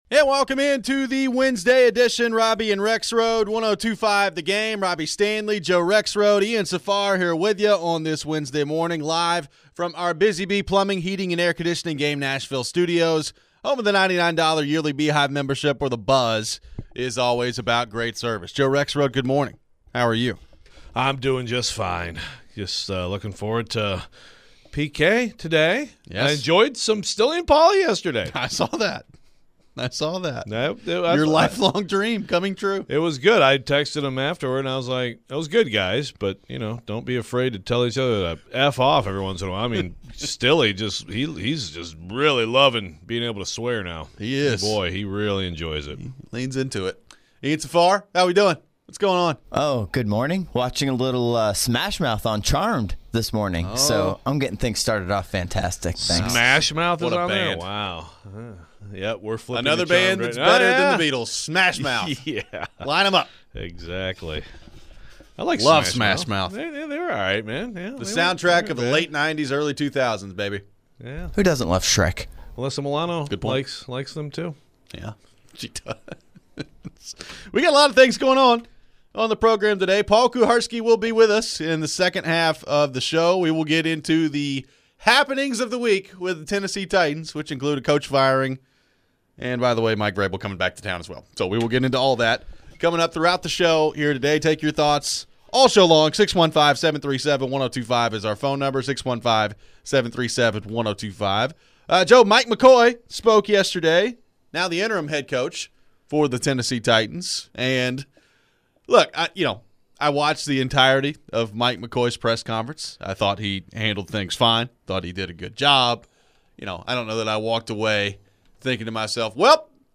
Who are some possible coaching candidates that could be let go by their current team at the end of the season? We take plenty of phones.